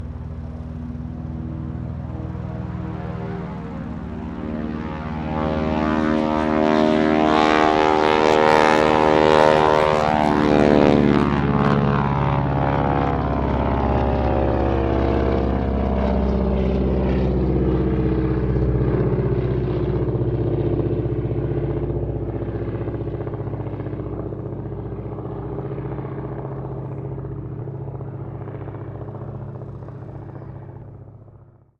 На этой странице собраны звуки военных самолетов разных типов: от рева реактивных двигателей до гула винтовых моделей.
Гул военных самолетов: небесная симфония Второй Мировой